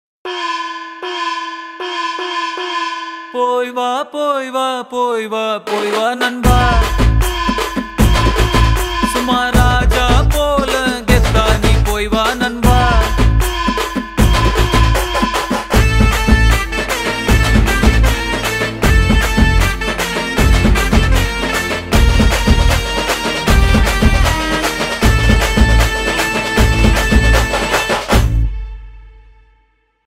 📂 Category Tamil Ringtones